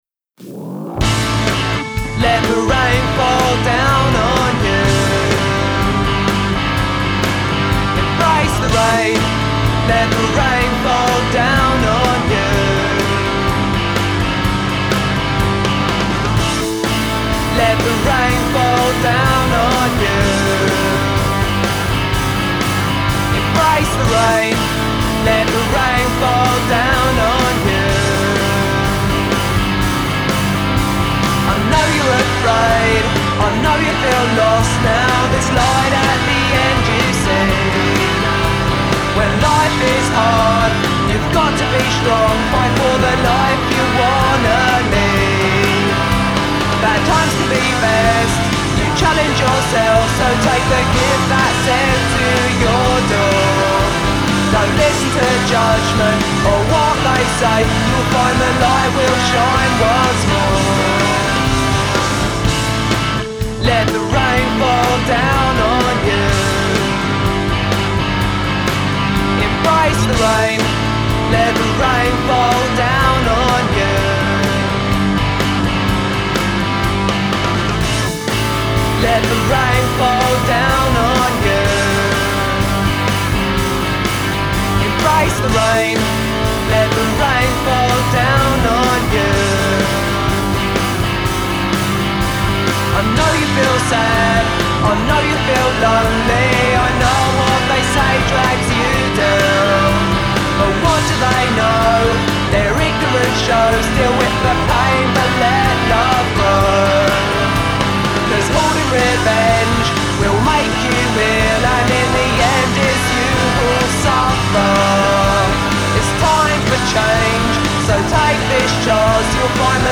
songwriter, guitarist, bassist & drummer
catchy "sing along" choruses and melodic guitars
"Punk" with a positive vibe and direction